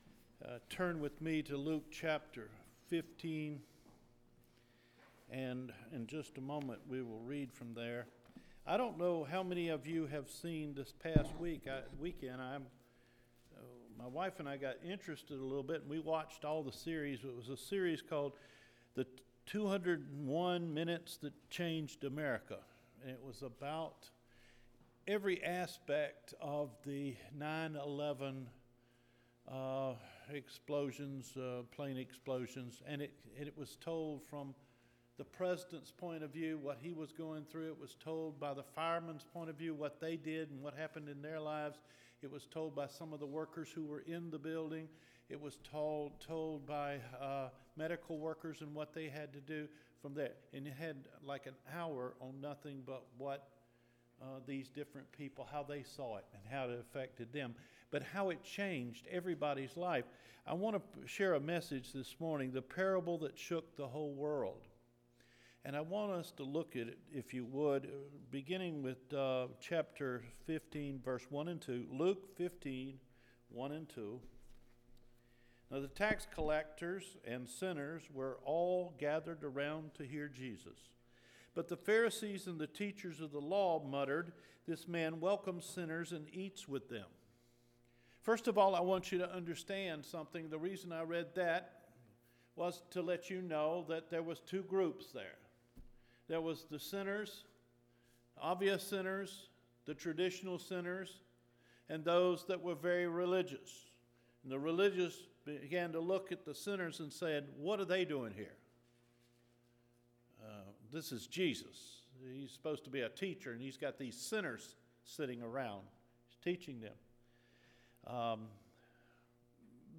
THE PARABLE THAT SHOOK THE ENTIRE WORLD – FEBRUARY 16 SERMON